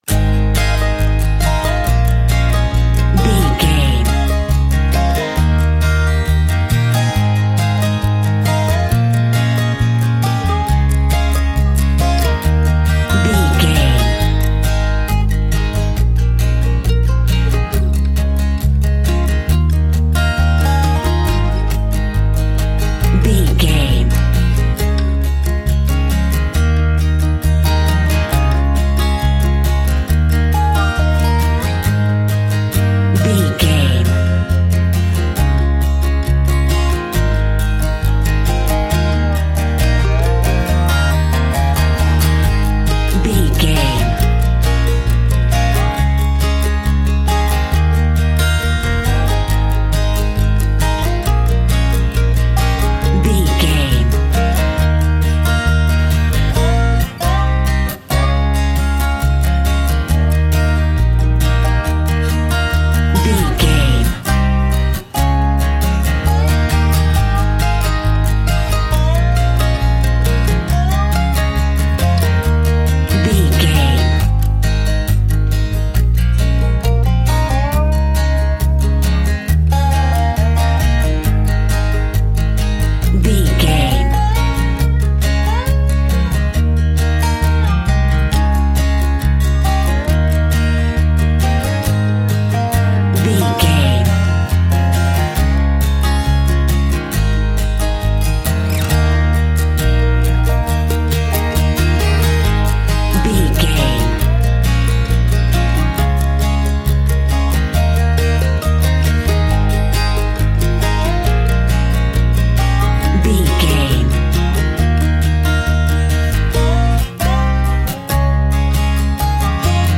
Aeolian/Minor
A♭
acoustic guitar